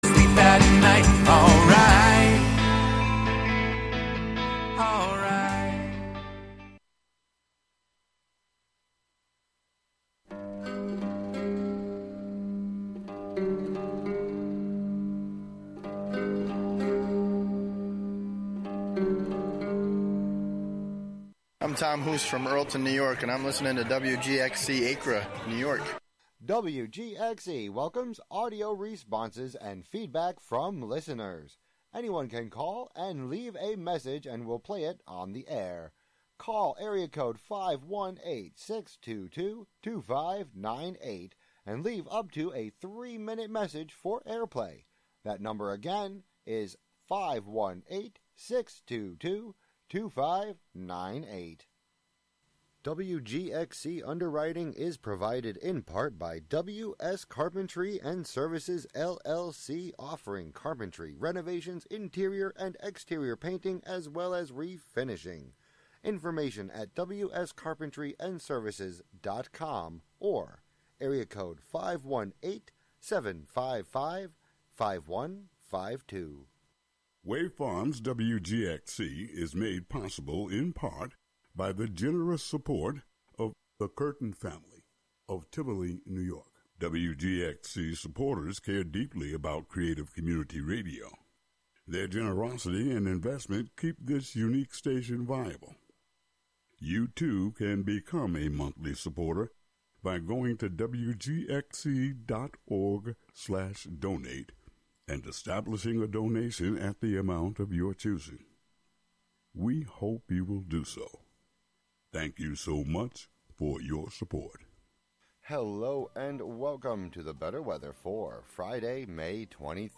Settle in with a cup of coffee, tea, or bourbon and join us in the conversation.